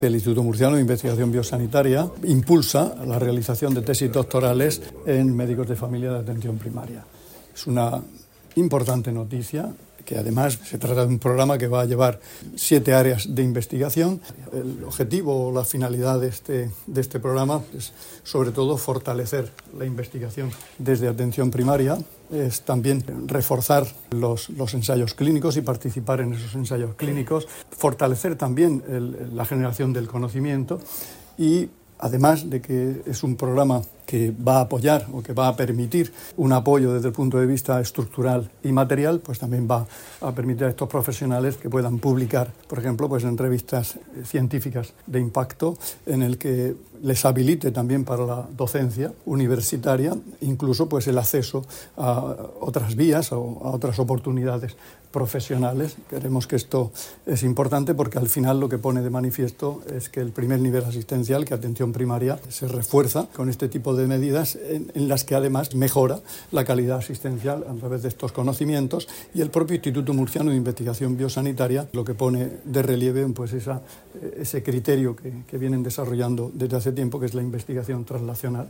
Declaraciones del consejero de Salud, Juan José Pedreño, sobre la iniciativa para que los médicos especialistas en Medicina Familiar y Comunitaria puedan realizar su tesis doctoral en siete áreas de investigación.